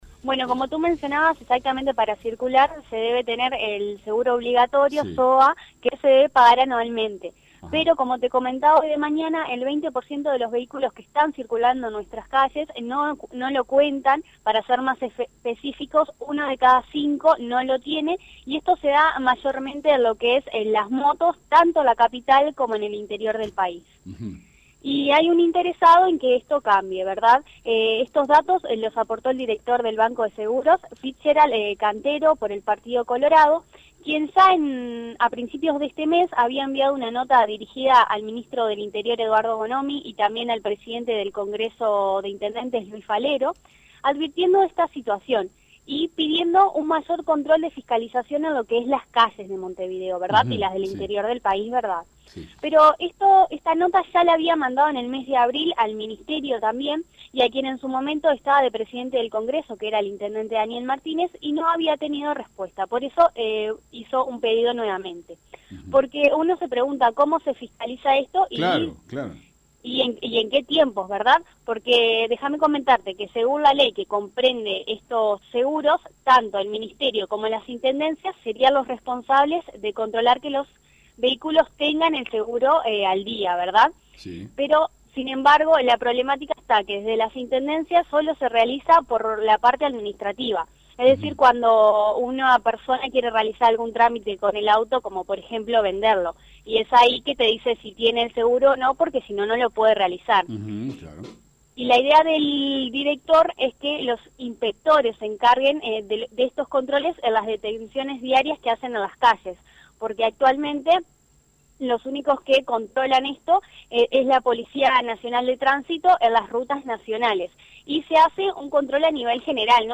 Aquí el informe completo: